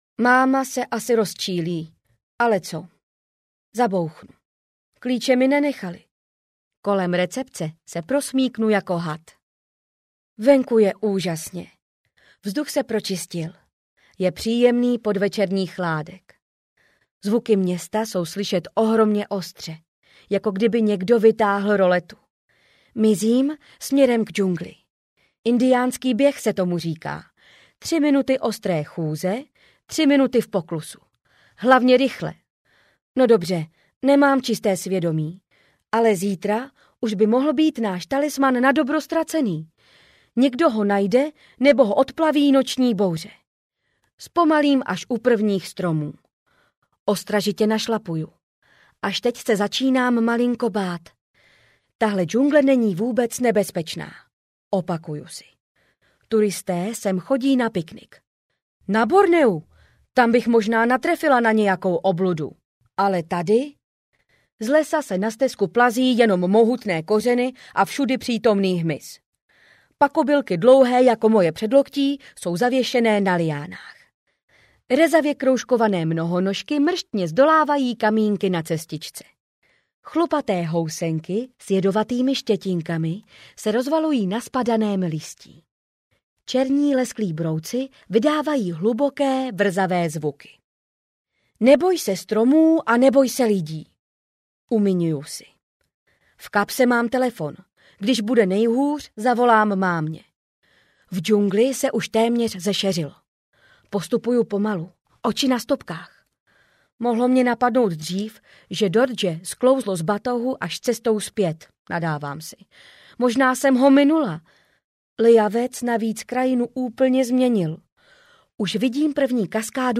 Annie a berlepsové audiokniha
Ukázka z knihy